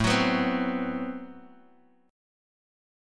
Am6add9 Chord
Listen to Am6add9 strummed